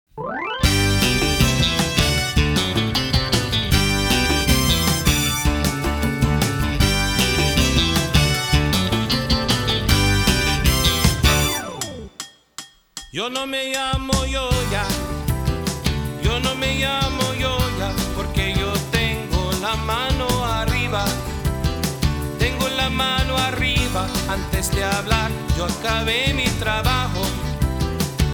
This a free style dance.